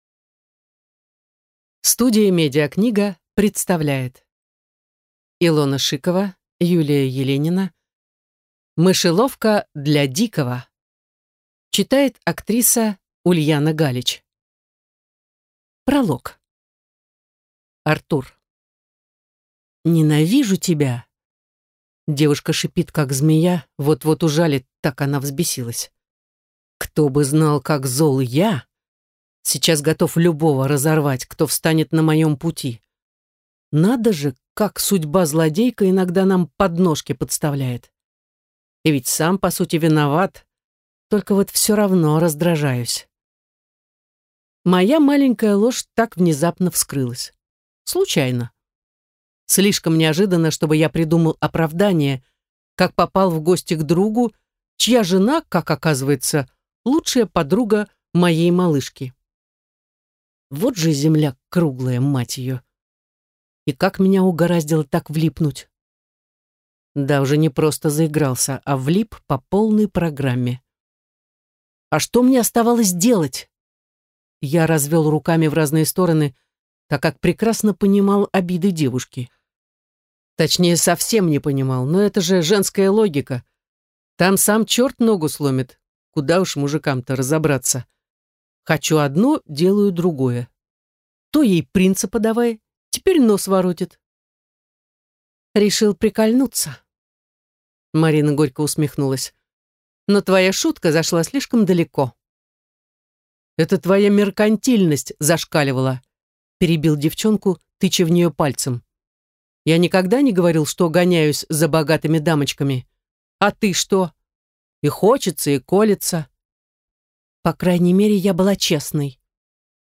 Аудиокнига Мышеловка для дикого | Библиотека аудиокниг